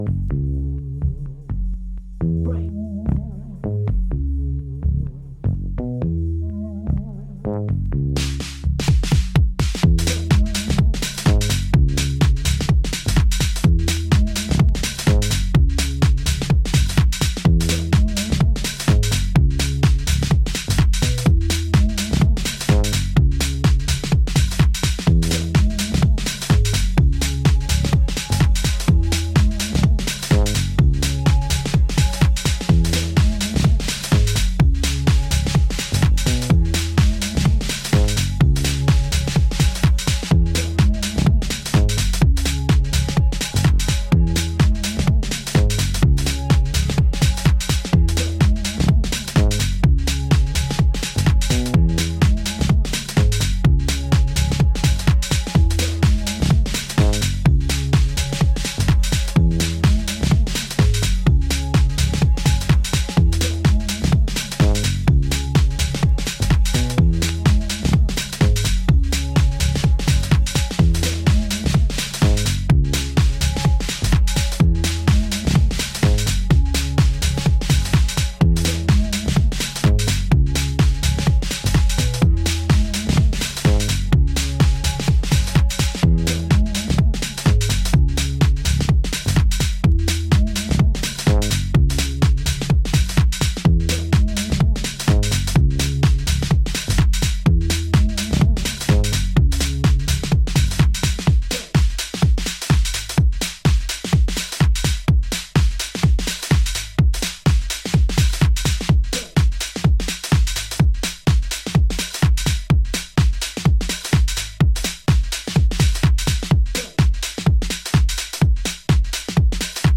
Electro House Acid